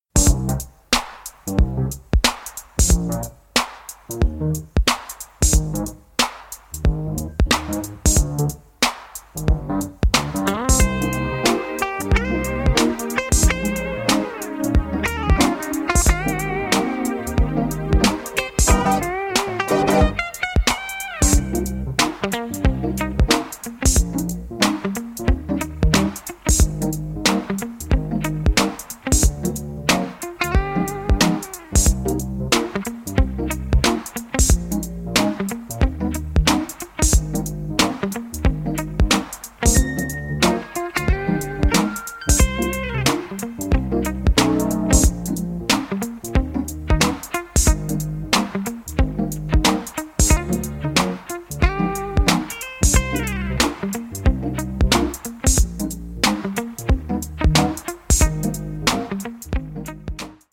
vintage, unreleased instrumental disco and boogie